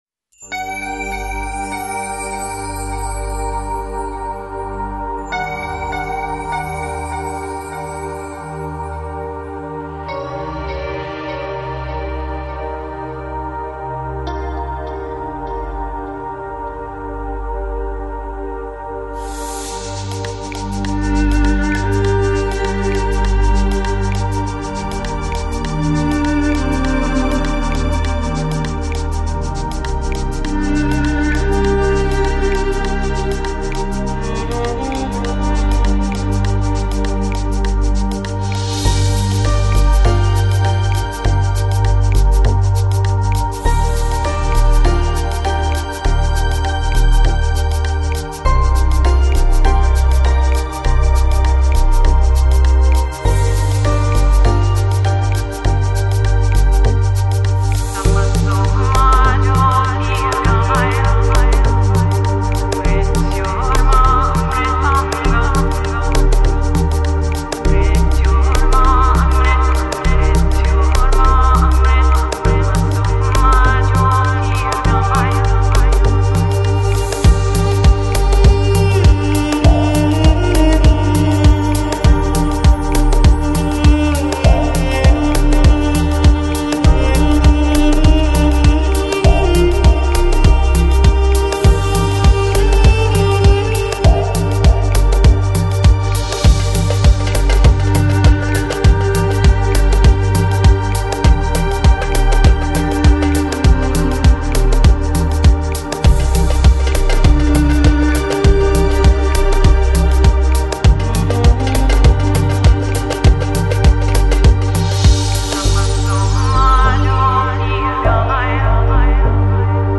Lounge, Chill House, Downtempo